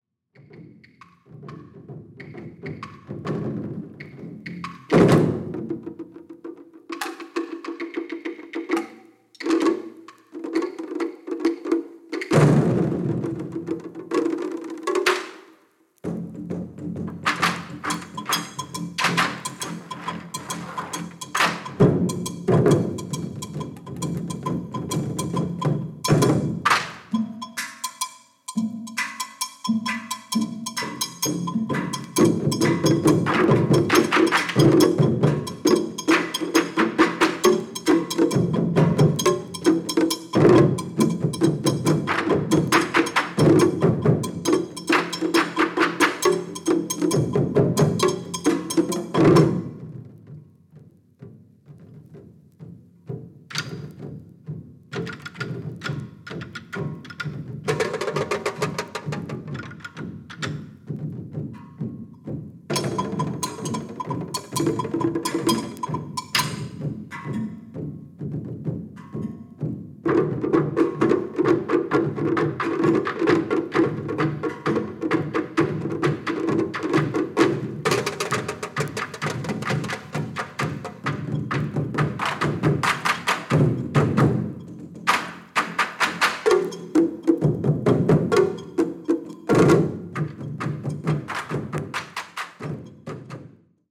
和太鼓＆津軽三味線です。
高音質盤！！！